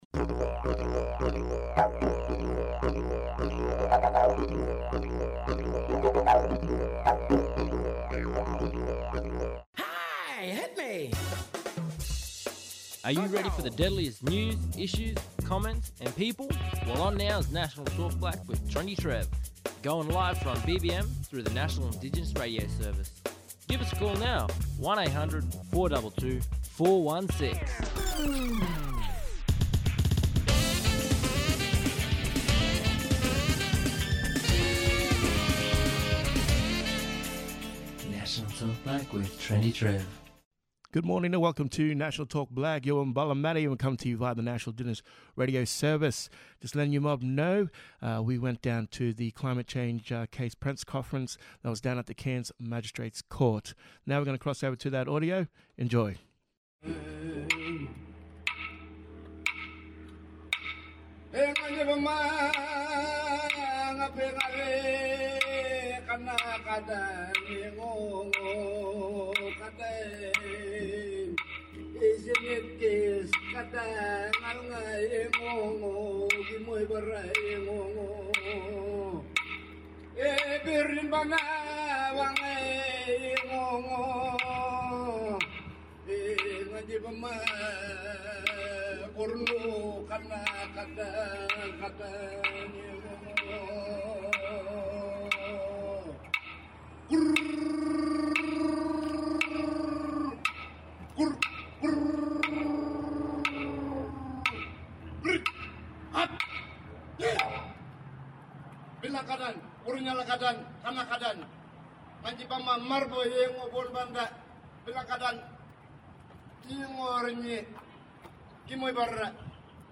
Traditional Island Dancing